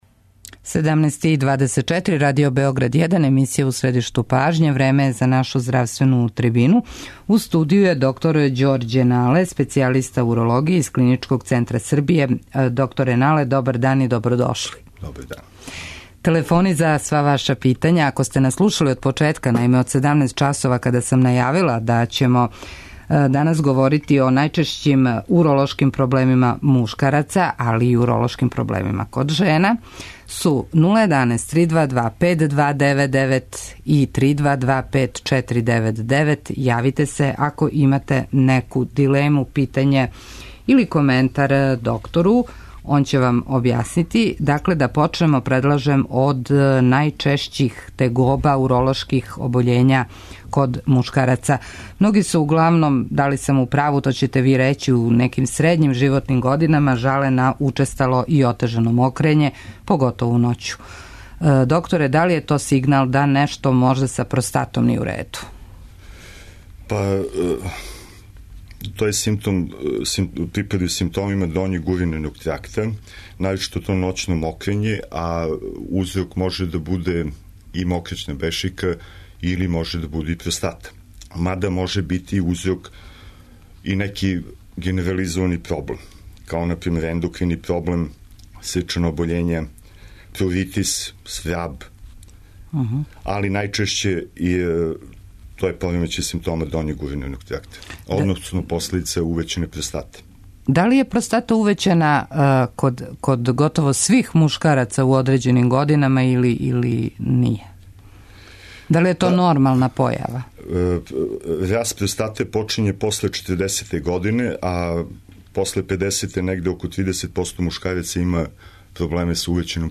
У здравственој трибини говоримо о најчешћим уролошким проблемима мушкараца.